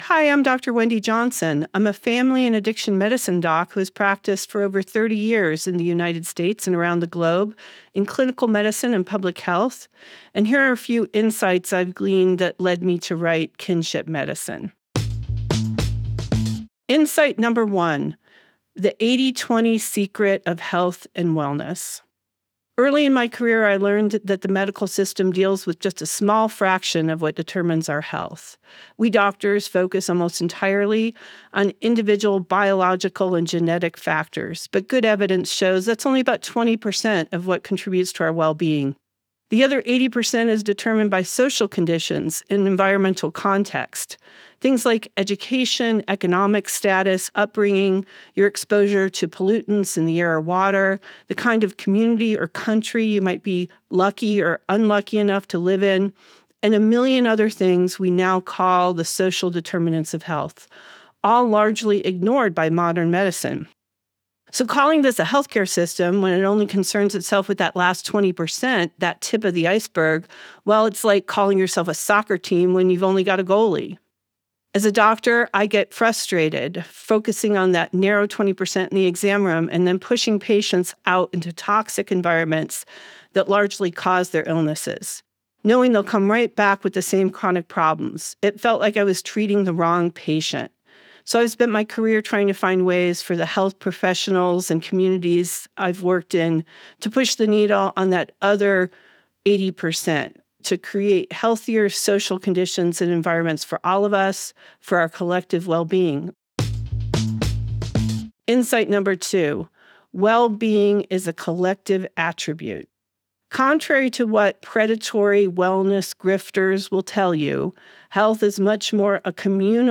Book Bites Environment Health